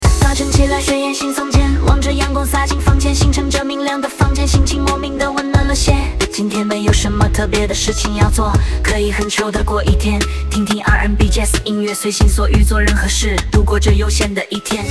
的今天沒有什麼特別的事情要做，可以很chill的過一天，聽聽R&B,Jass音樂，隨心所欲做任何事……度過這悠閒的一天
人工智能生成式歌曲